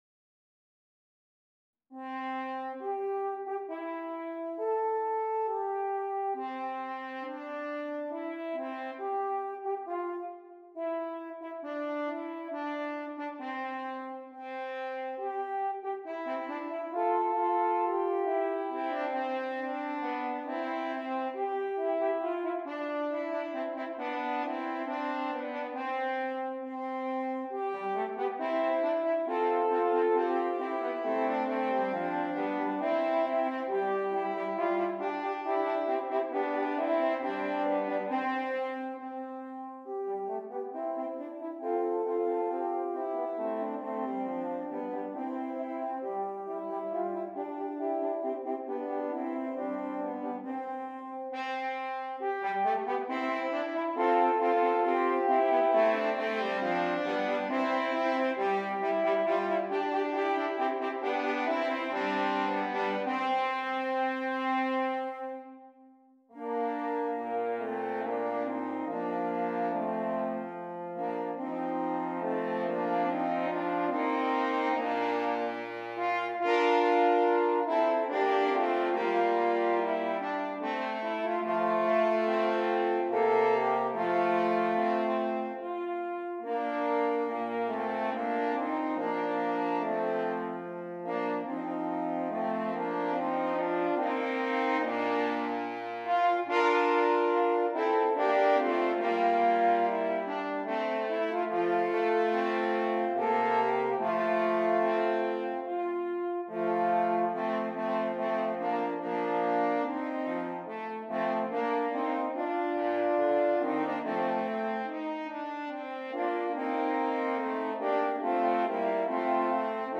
Brass
3 F Horns